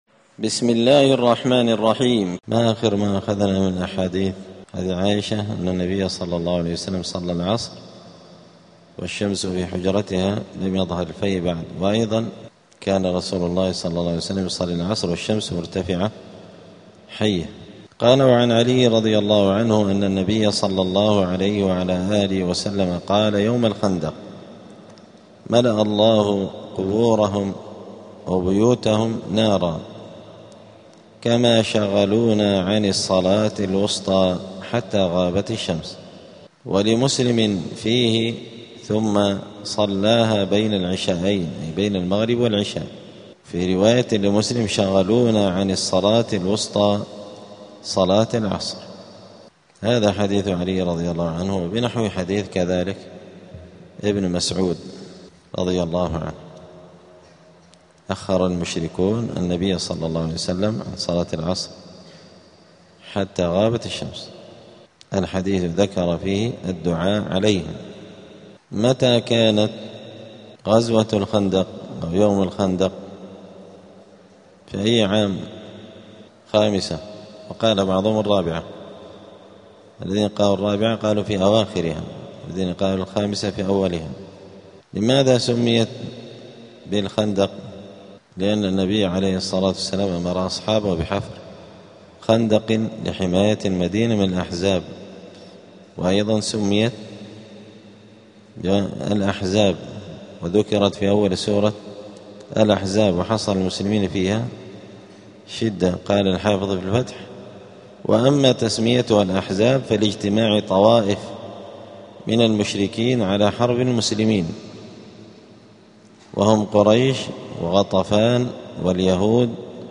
دار الحديث السلفية بمسجد الفرقان قشن المهرة اليمن
*الدرس الخامس والثلاثون بعد المائة [135] {حكم تأخير صلاة العصر}*